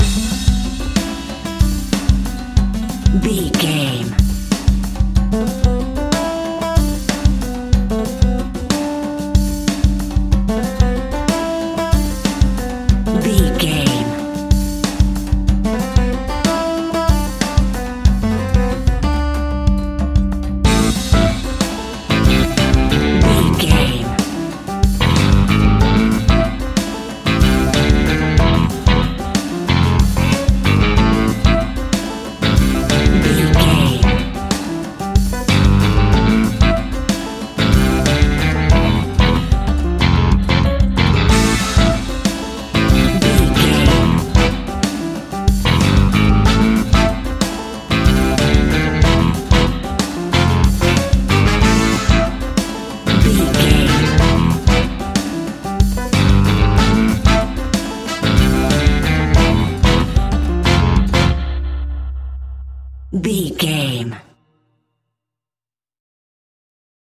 Aeolian/Minor
latin
uptempo
brass
saxophone
trumpet